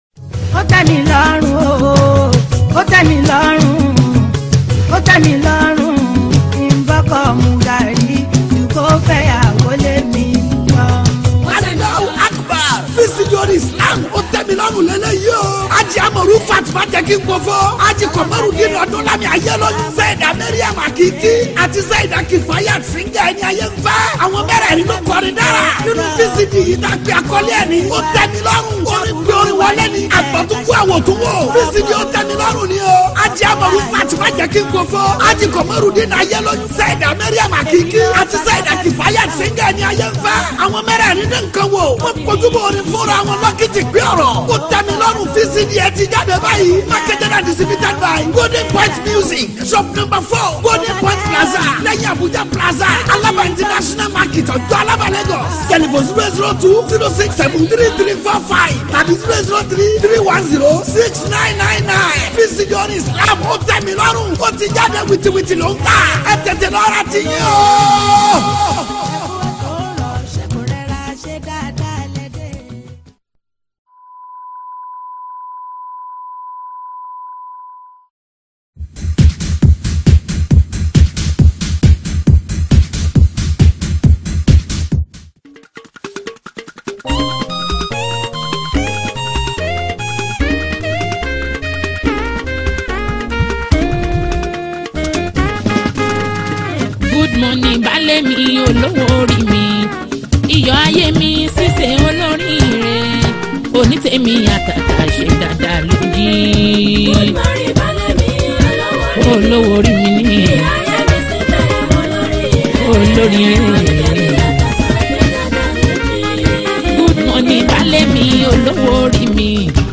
Nigerian Yoruba Fuji track
Fuji Music